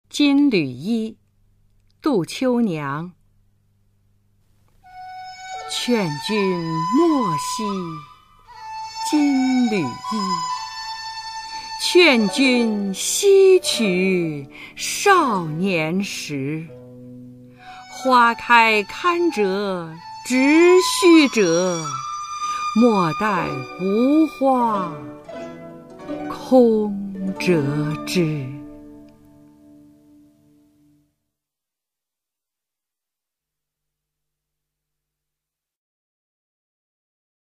[隋唐诗词诵读]杜秋娘-金缕衣 古诗文诵读